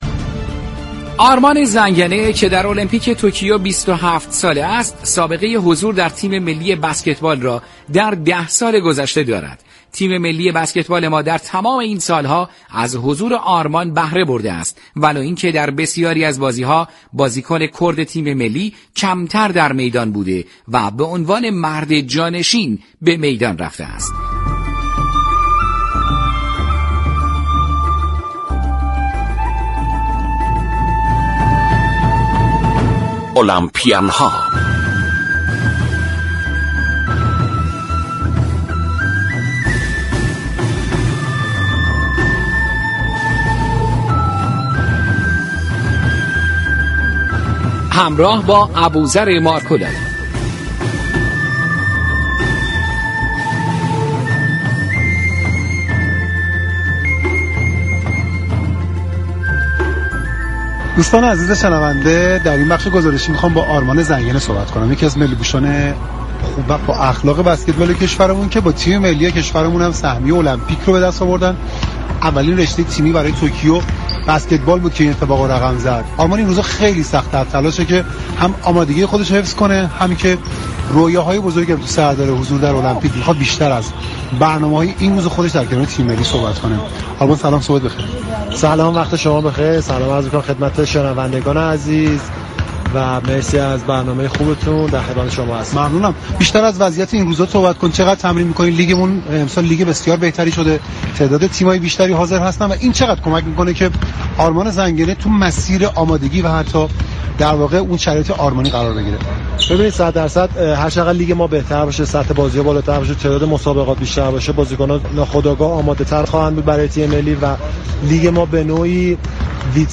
برنامه